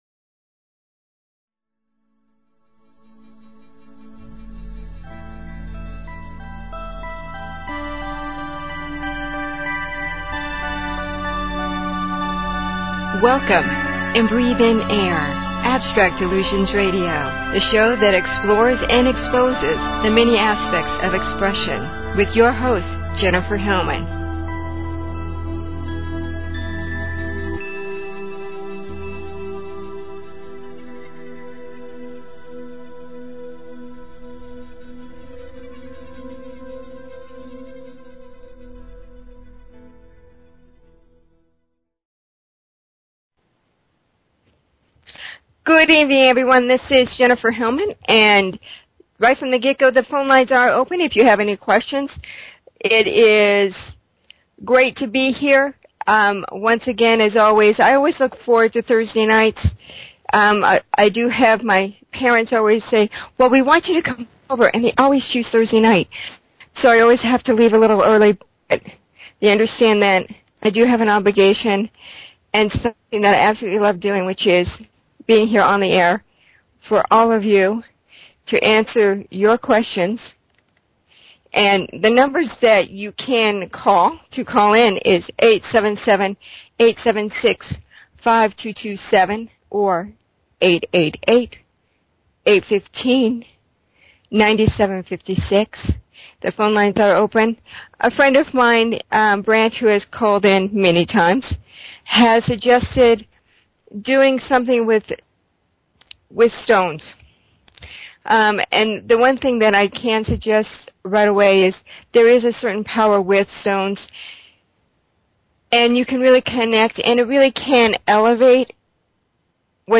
Talk Show Episode, Audio Podcast, Abstract_Illusion_Radio and Courtesy of BBS Radio on , show guests , about , categorized as
The open lines of discussion on a variety of subjects with the audience.